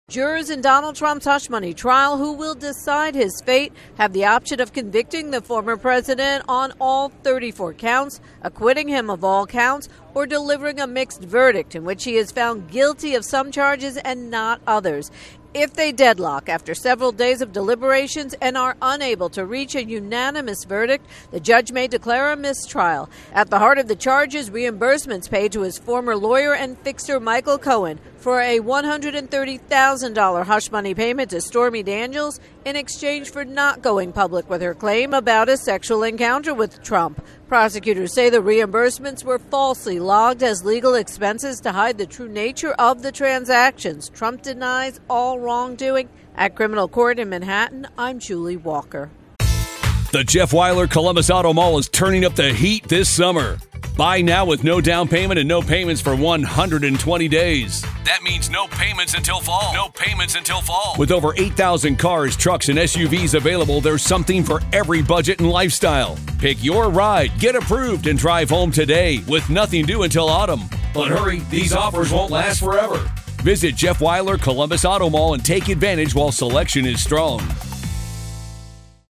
reports from New York